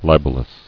[li·bel·ous]